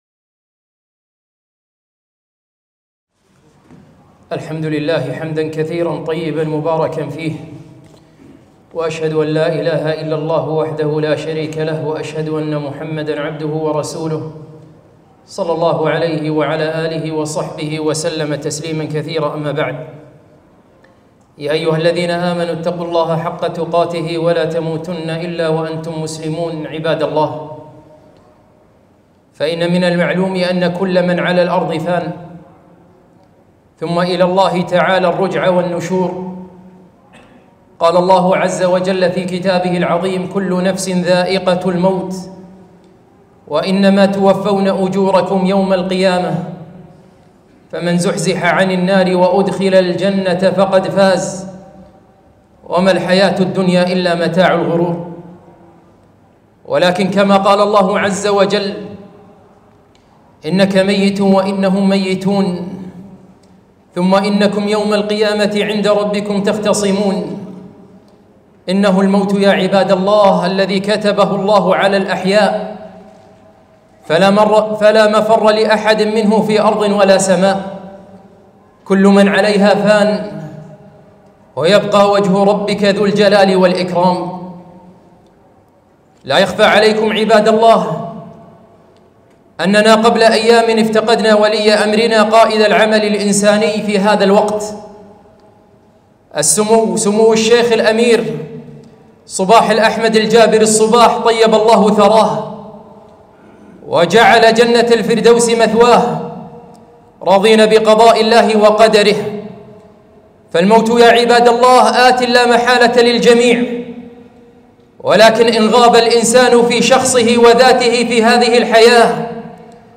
خطبة - وفاة الأمير